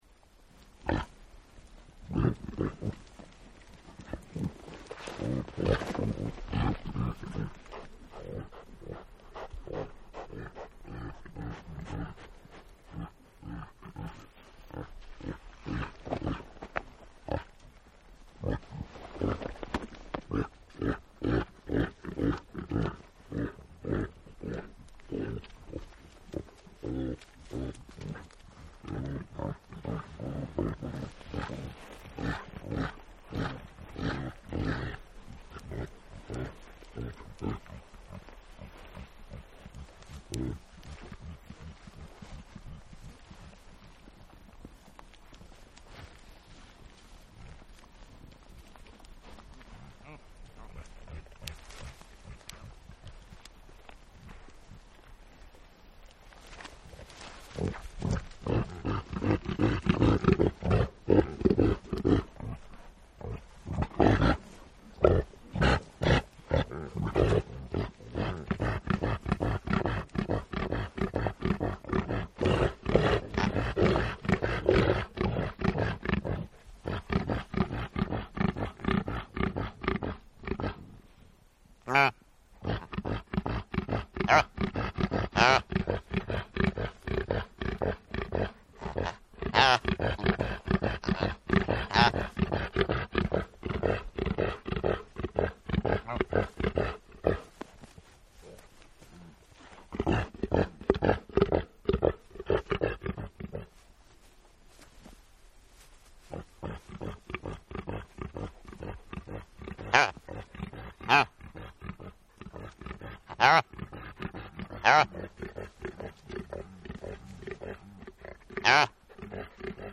Здесь собраны натуральные записи: от мягкого перестука копыт до мощного рёва во время гона.
Северные олени хрюкают